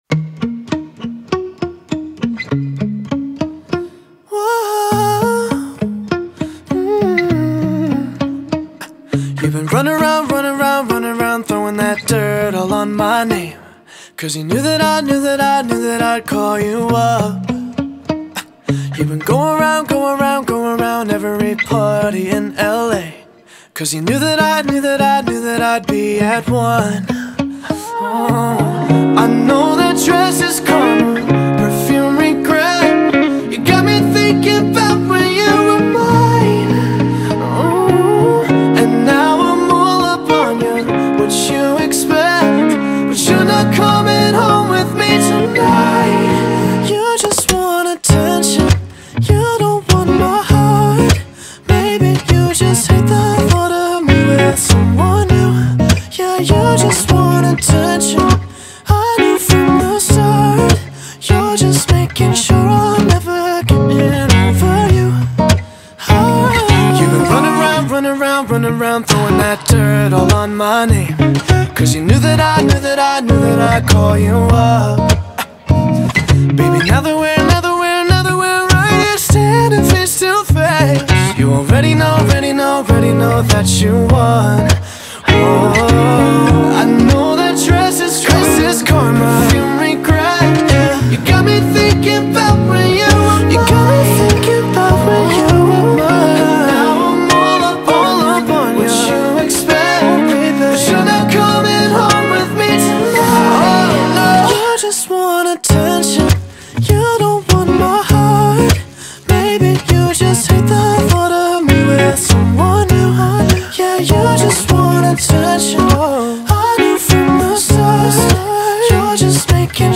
в акустическом исполнении